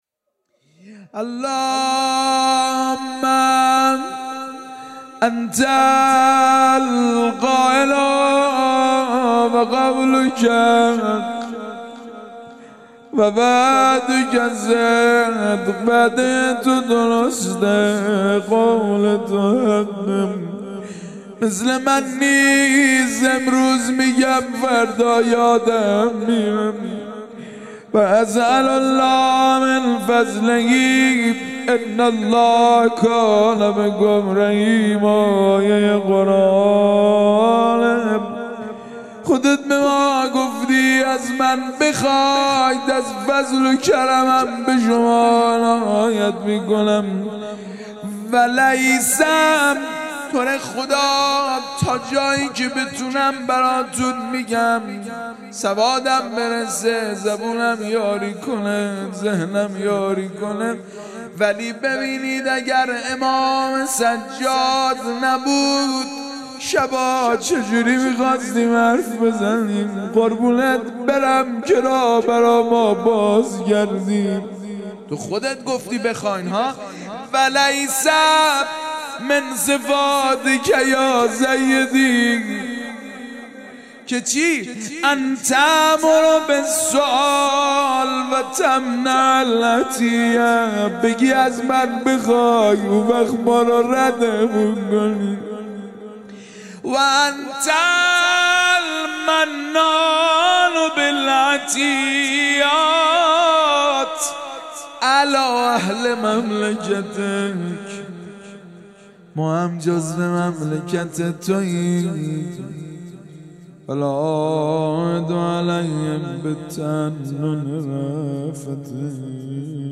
مداحی
در مسجد کربلا برگزار گردید.
غزل مناجات لینک کپی شد گزارش خطا پسندها 0 اشتراک گذاری فیسبوک سروش واتس‌اپ لینکدین توییتر تلگرام اشتراک گذاری فیسبوک سروش واتس‌اپ لینکدین توییتر تلگرام